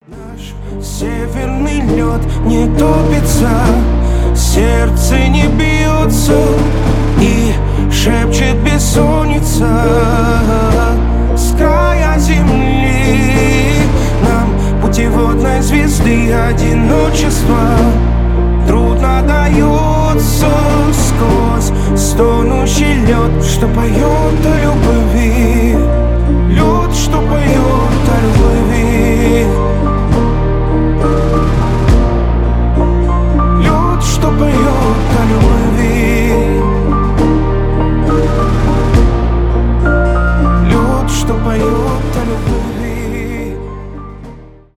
поп
романтические